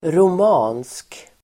Uttal: [rom'a:nsk]